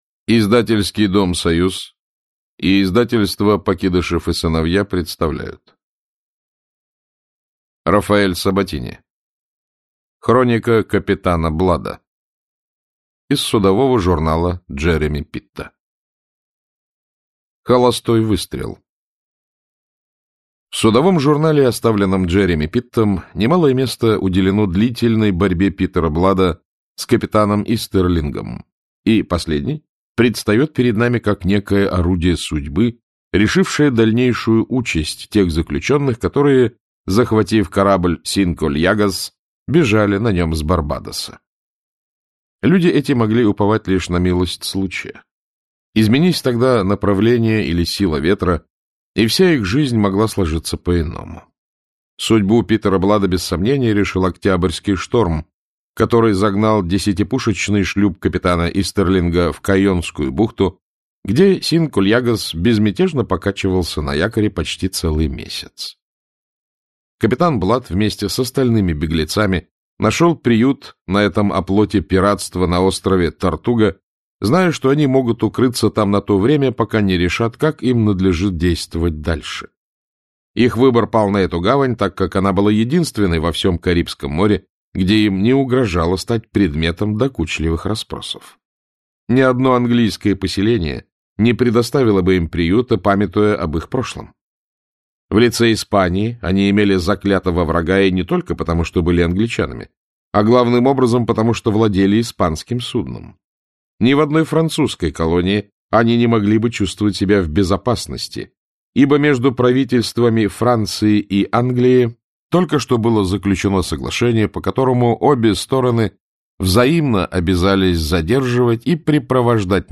Аудиокнига Хроника капитана Блада | Библиотека аудиокниг